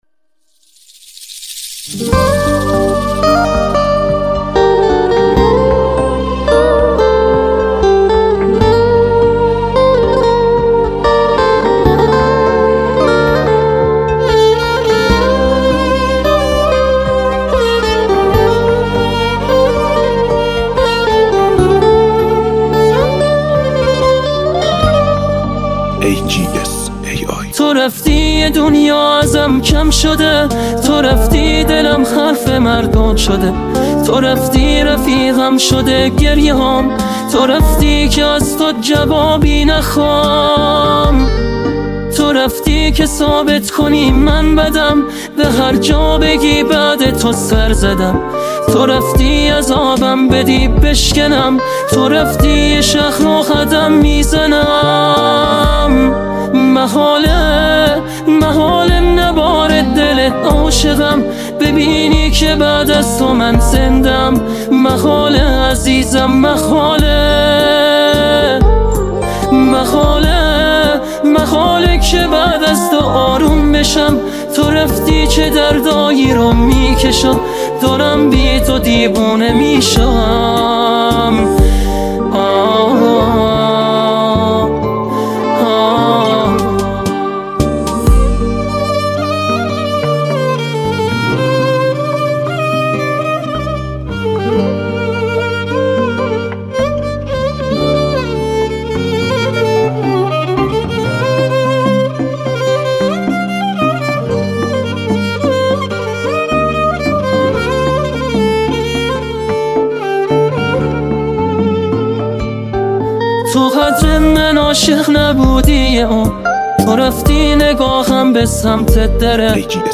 اهنگ پاپ ایرانی